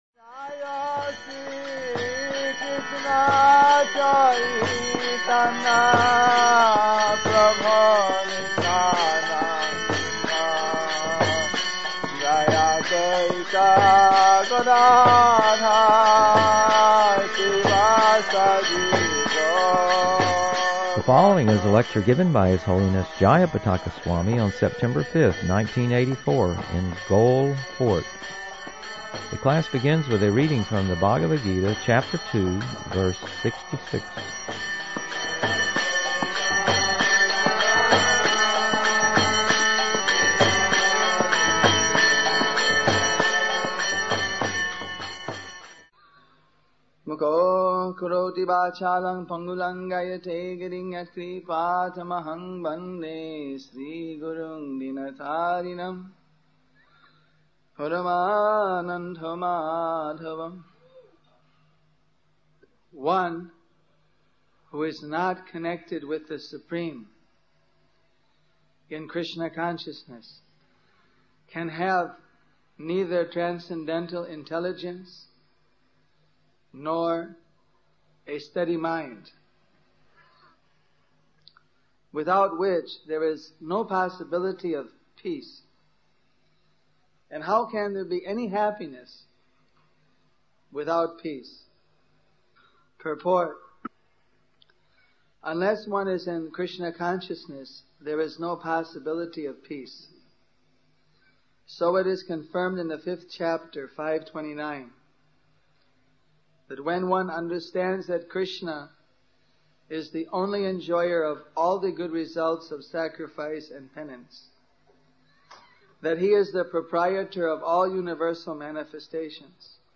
The class begins with a reading from the Bhagavad-gita, Chapter 2, Verse 66.